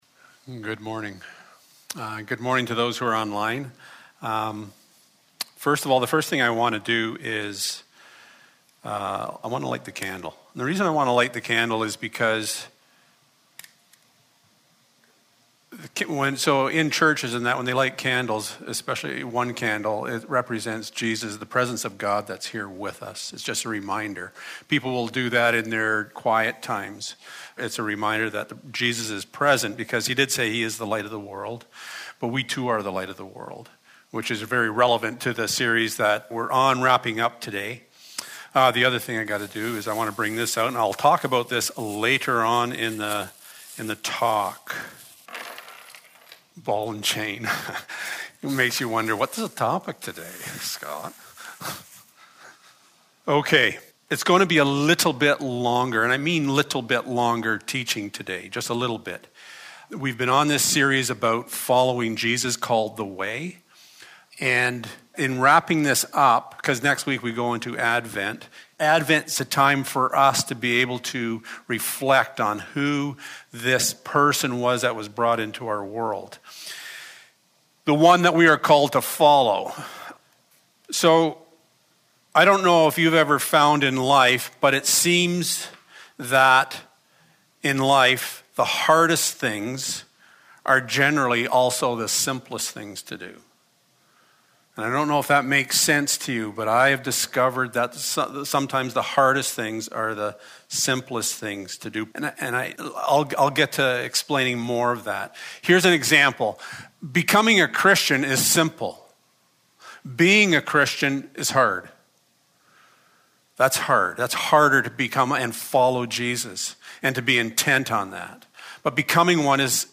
The Way Service Type: Sunday Morning Following Jesus is simple